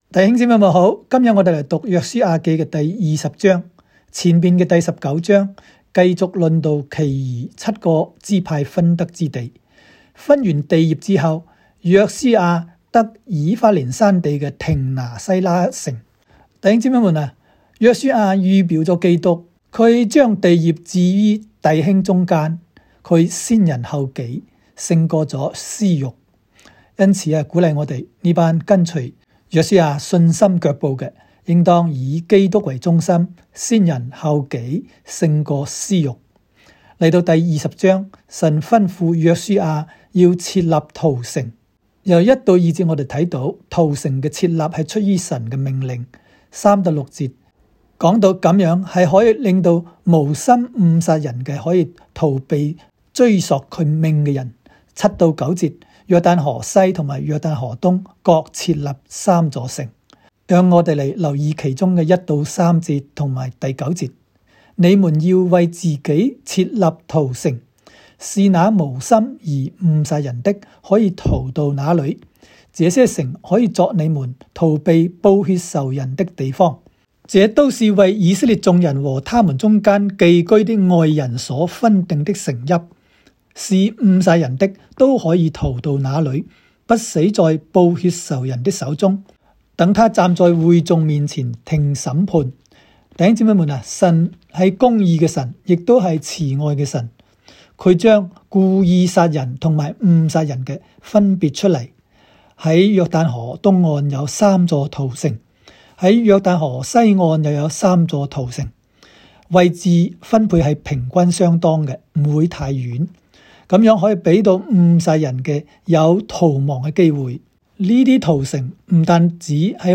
书20（讲解-粤）.m4a